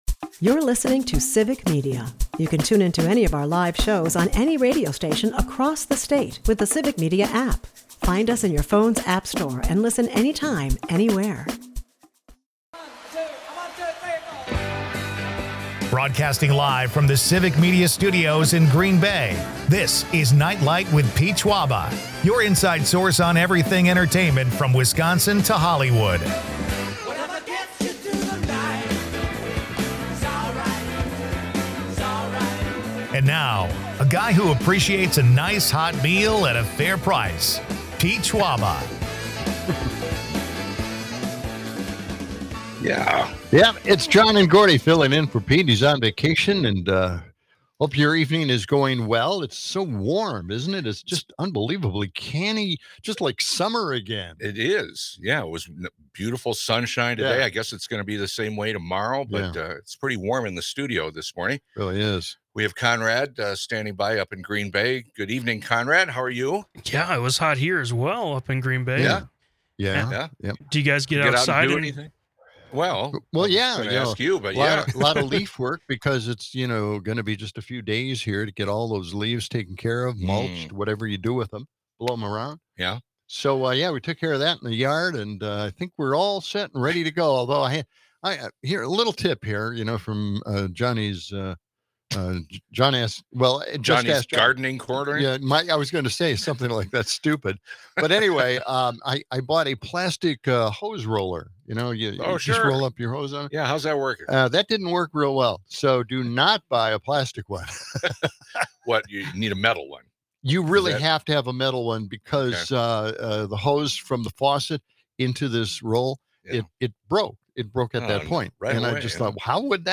A lively debate on favorite chip dips sees listeners weigh in with love for guacamole, queso blanco, and more.